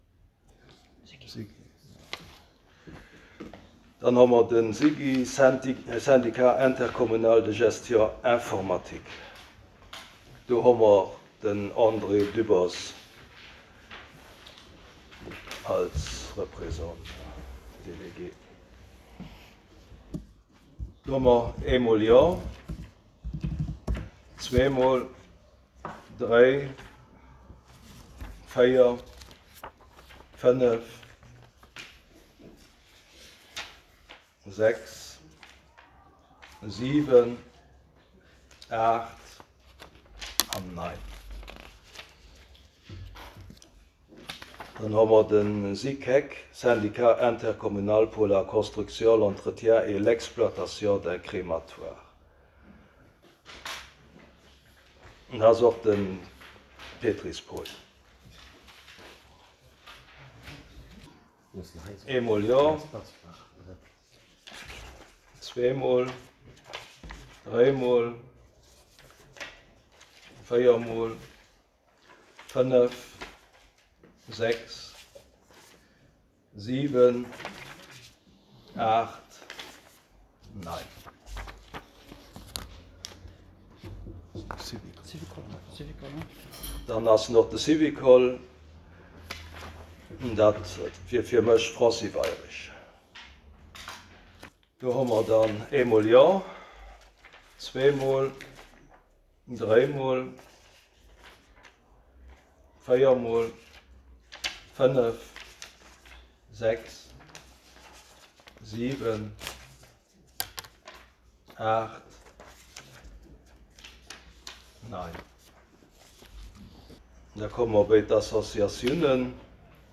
Conseil Communal du mercredi,19 juillet 2023 à 14.00 heures en la salle Bessling du Centre Culturel Larei